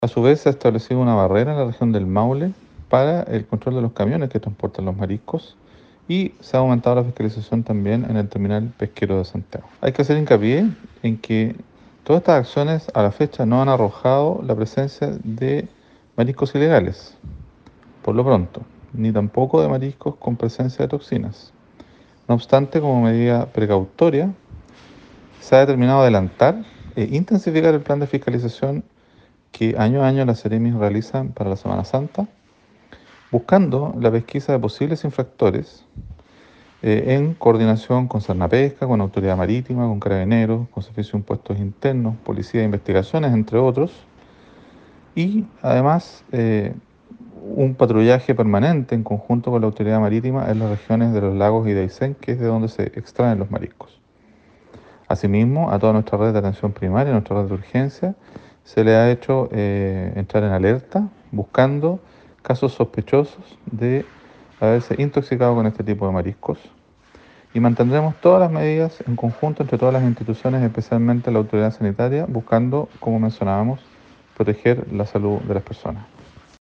La autoridad expresó que las medidas de control continúan a lo largo de la ruta 5, y además, se adelantó el plan de fiscalización en la región.